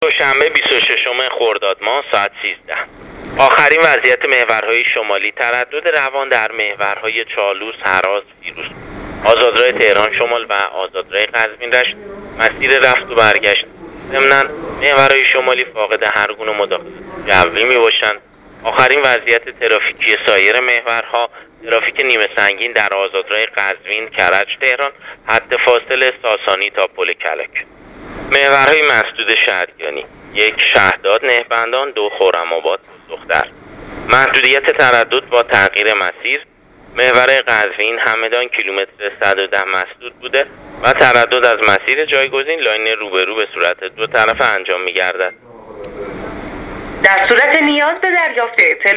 گزارش رادیو اینترنتی از وضعیت ترافیکی جاده‌ها تا ساعت ۱۳ بیست و ششم خرداد